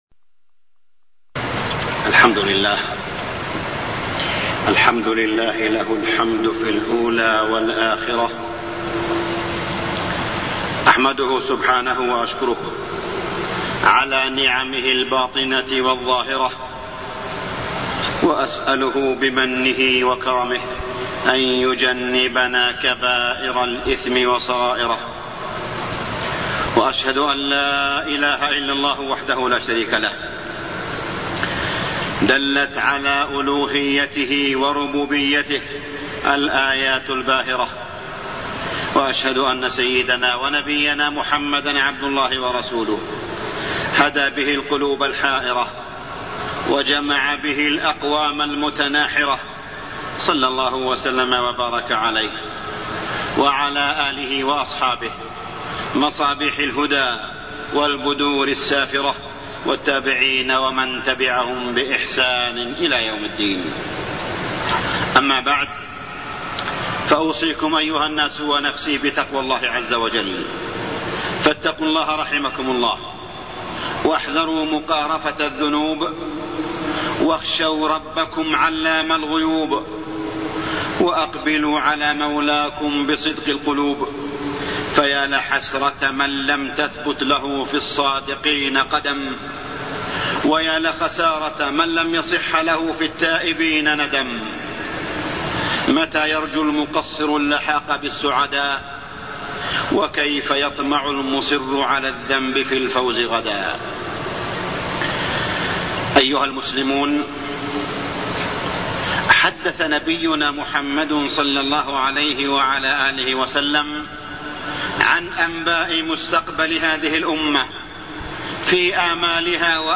تاريخ النشر ٧ شعبان ١٤٢١ هـ المكان: المسجد الحرام الشيخ: معالي الشيخ أ.د. صالح بن عبدالله بن حميد معالي الشيخ أ.د. صالح بن عبدالله بن حميد نبوة النبي صلى الله عليه وسلم